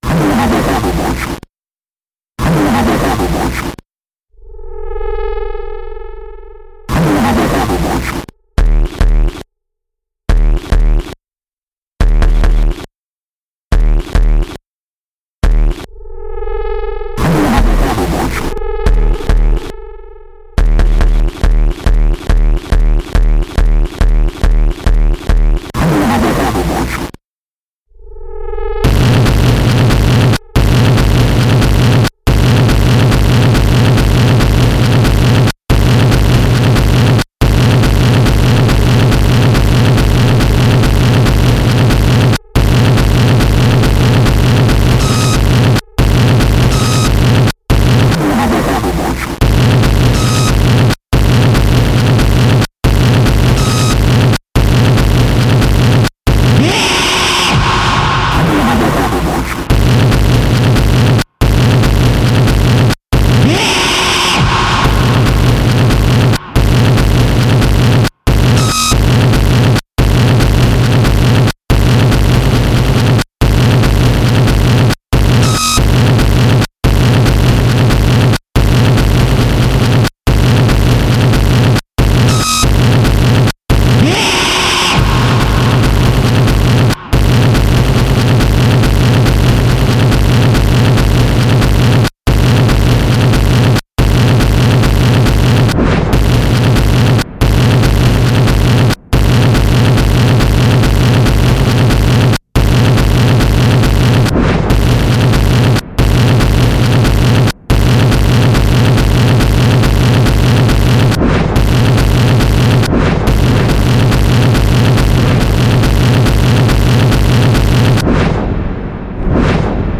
EBM, Industrial, Rhythmic Noise